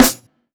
zetwy_snr.wav